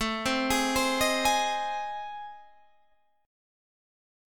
AmM7b5 Chord
Listen to AmM7b5 strummed